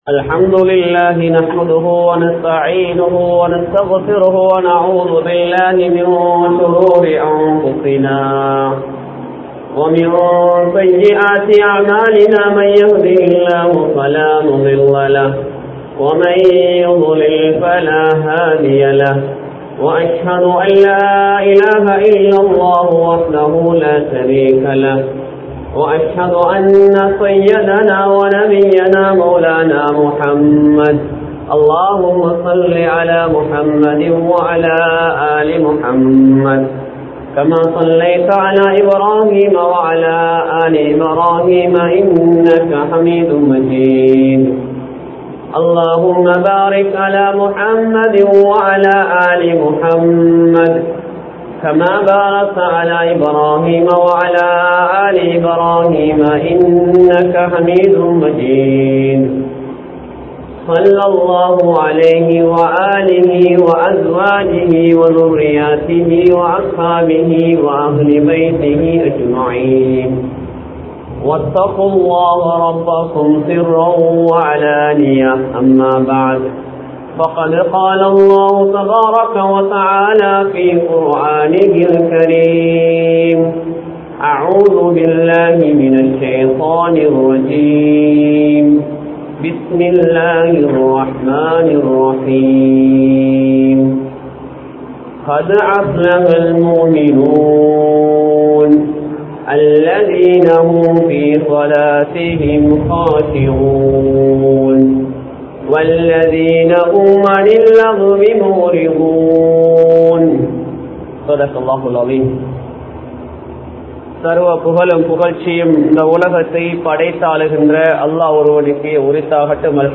Islaththin Paarvaiel Arasiyal (இஸ்லாத்தின் பார்வையில் அரசியல்) | Audio Bayans | All Ceylon Muslim Youth Community | Addalaichenai
Gothatuwa, Jumua Masjidh